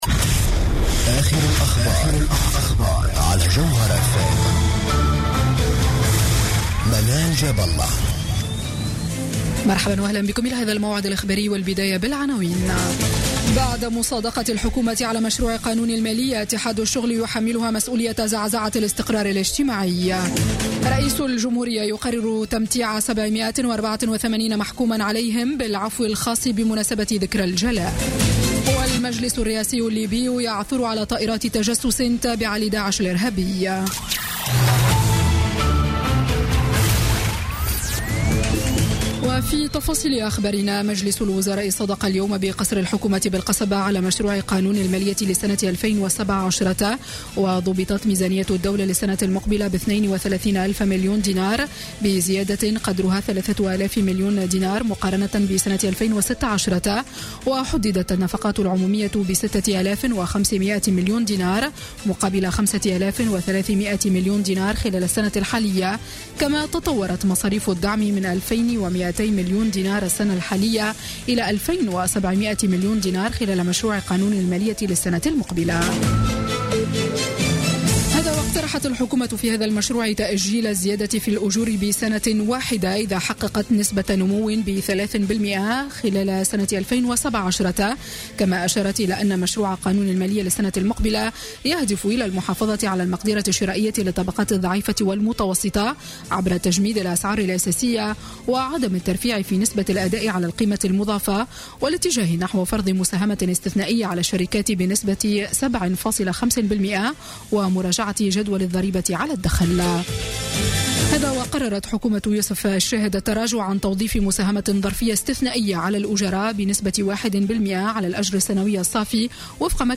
نشرة أخبار السابعة مساء ليوم الجمعة 14 أكتوبر 2016